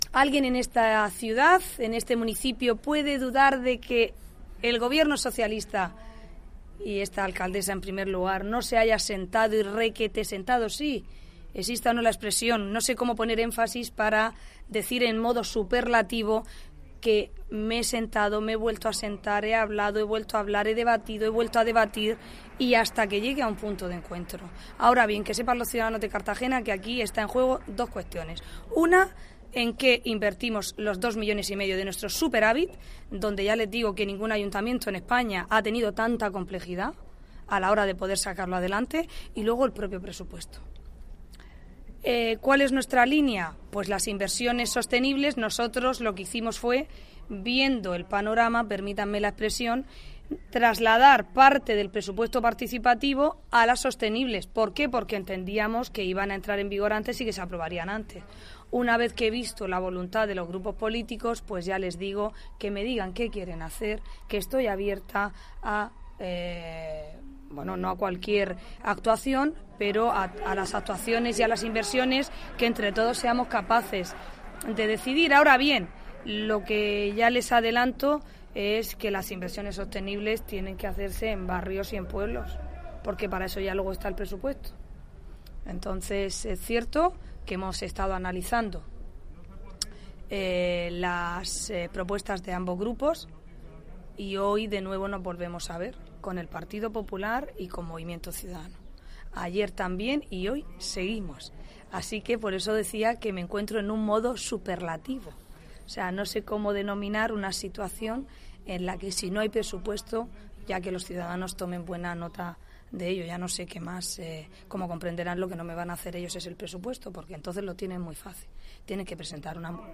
Ana Belén Castejón, al ser preguntada por los periodistas sobre este asunto, ha manifestado que nadie en esta ciudad puede dudar de que el gobierno está reuniéndose con el resto de grupos, para negociar los presupuestos.